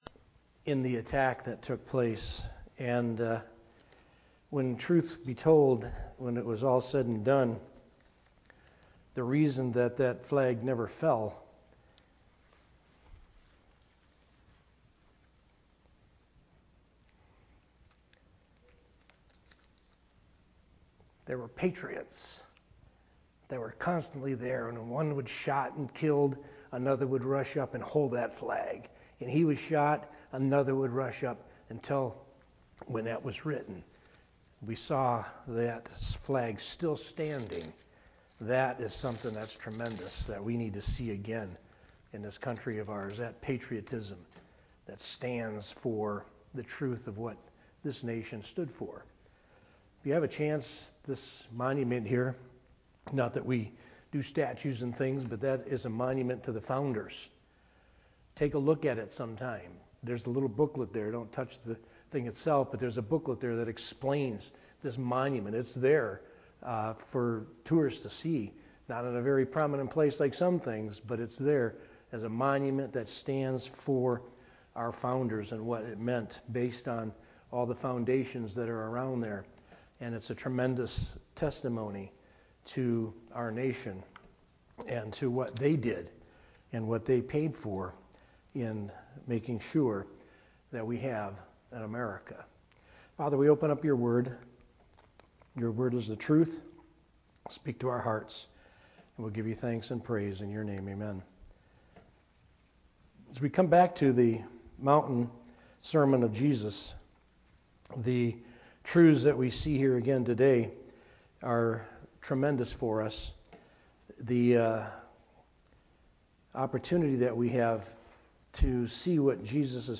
A message from the series "Sunday Morning - 11:00."
Sermon